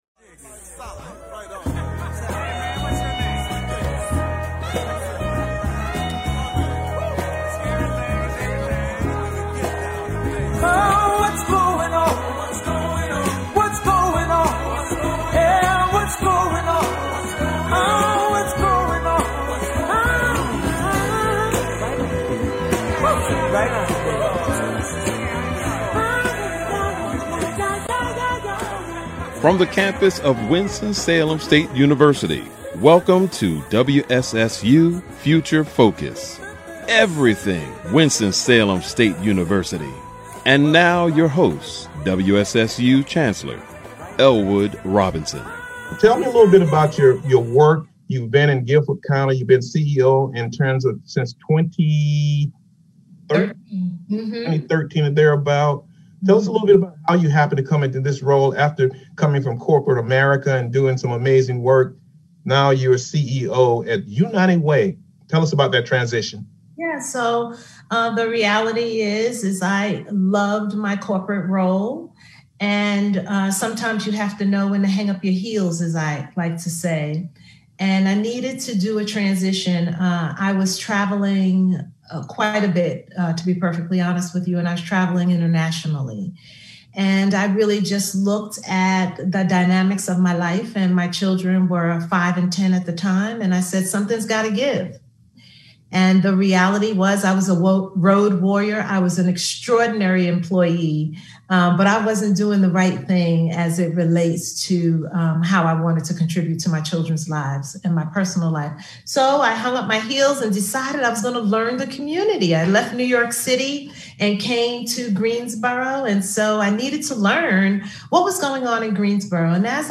Future Focus is a one-hour public affairs talk show hosted by Winston-Salem State University's Chancellor Elwood Robinson.